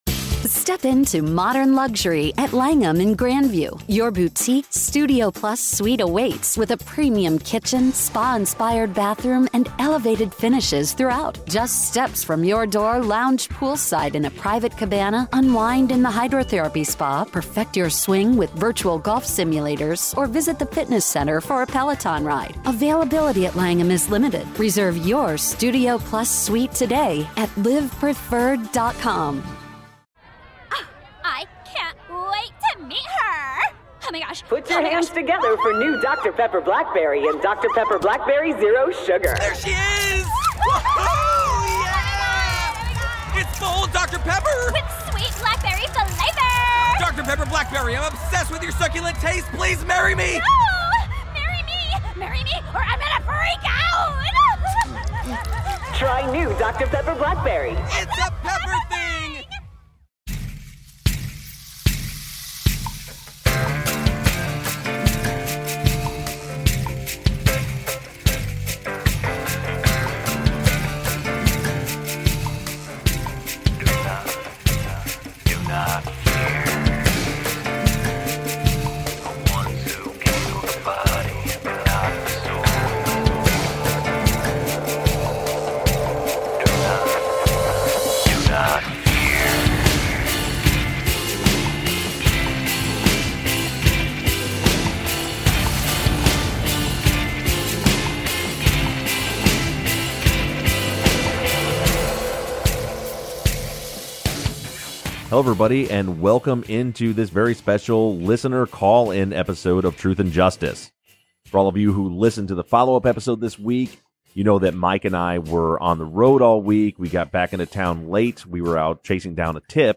Listener Call-Ins